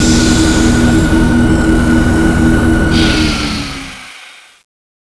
Index of /cstrike/sound/turret
tu_search.wav